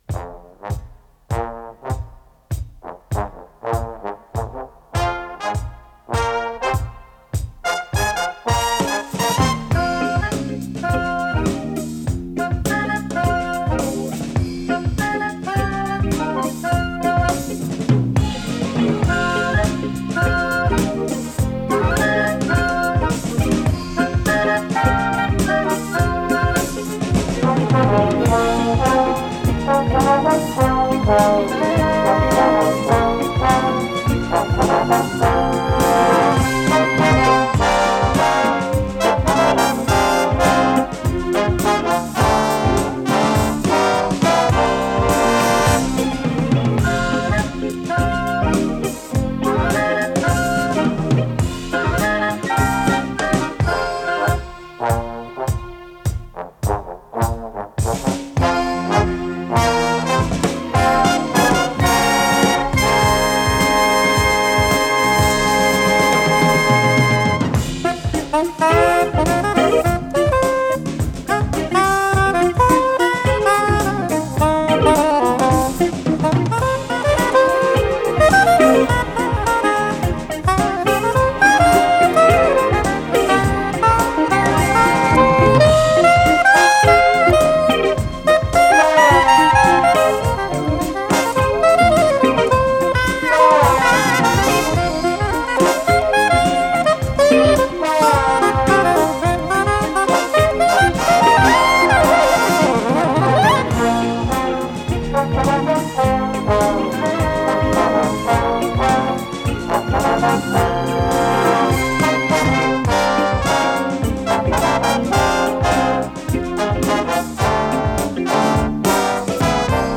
с профессиональной магнитной ленты
РедакцияМузыкальная
ВариантДубль моно